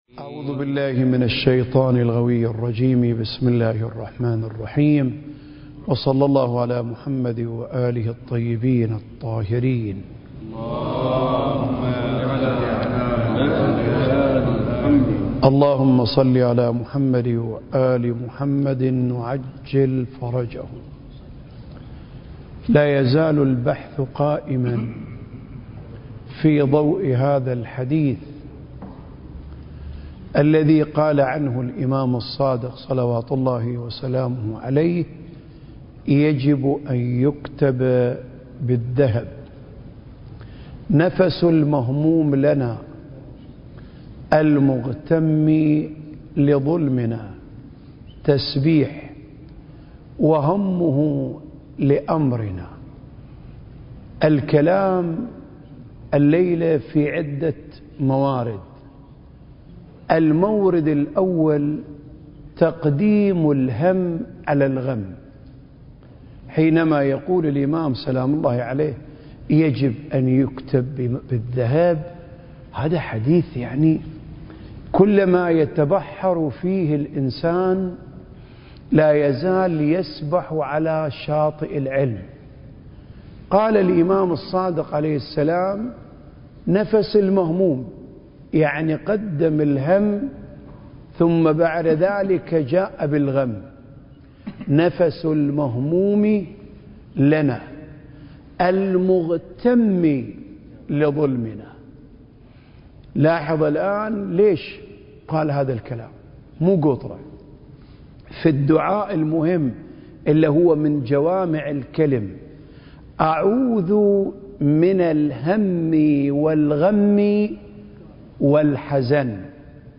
سلسلة محاضرات: آفاق المعرفة المهدوية (10) المكان: الأوقاف الجعفرية بالشارقة التاريخ: 2023